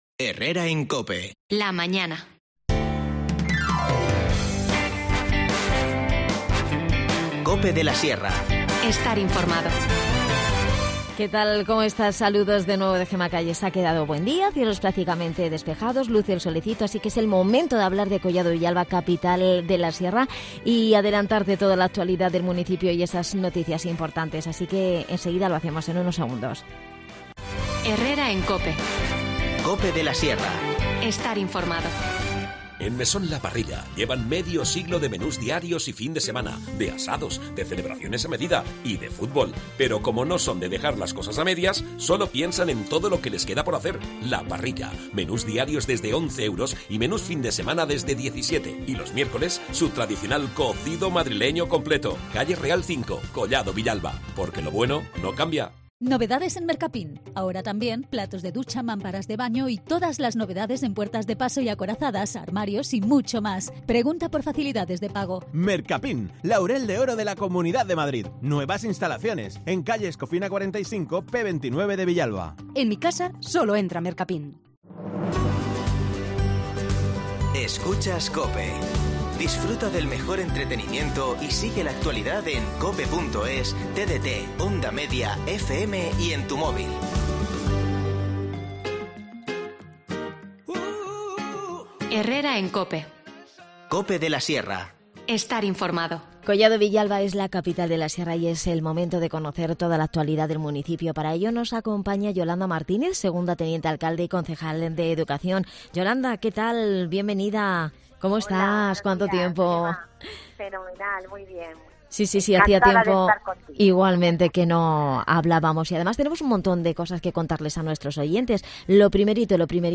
Yolanda Martínez, concejal de Educación nos habla de estas y otras noticias.